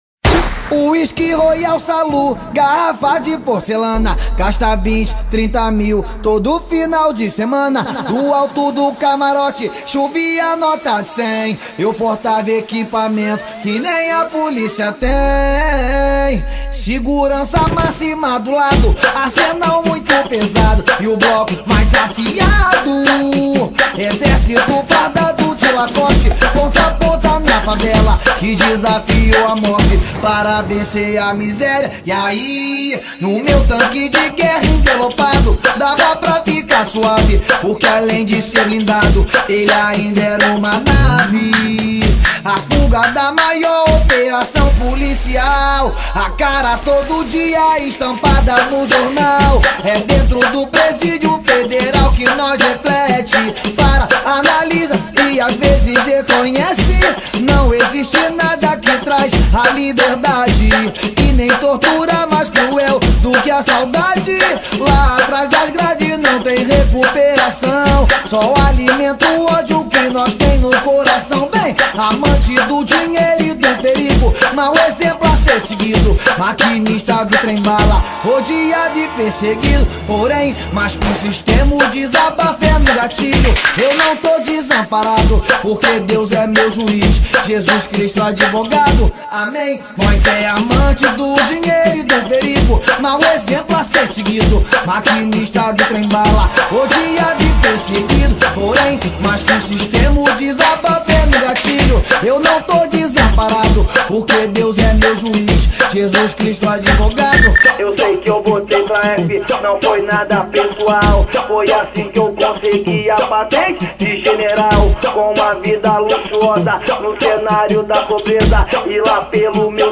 2024-12-19 01:07:46 Gênero: Funk Views